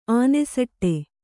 ♪ ānesaṭṭe